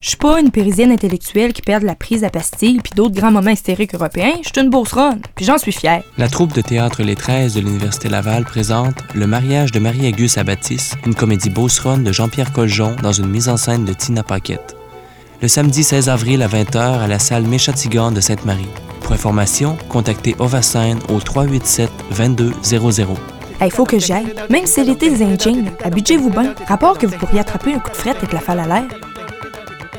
Publicité radio de Sainte-Marie (mp3)